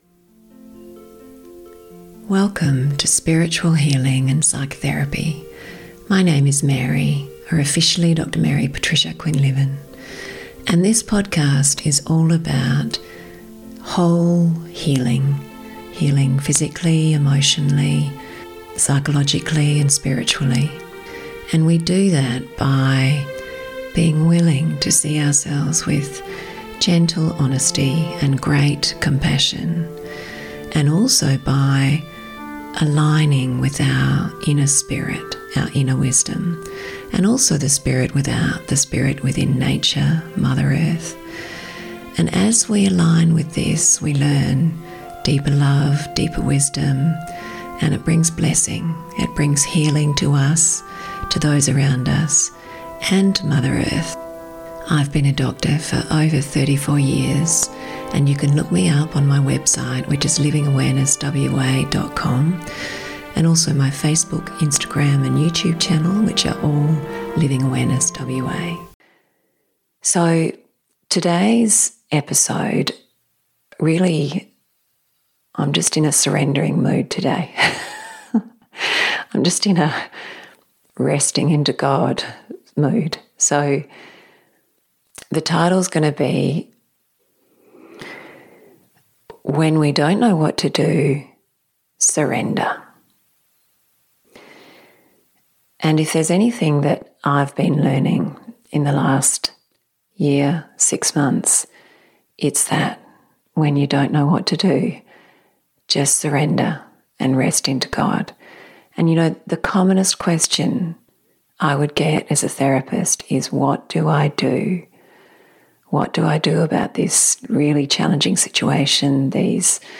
So there's definitely a few tears in this one.